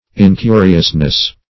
Search Result for " incuriousness" : The Collaborative International Dictionary of English v.0.48: Incuriousness \In*cu"ri*ous*ness\, n. Unconcernedness; incuriosity.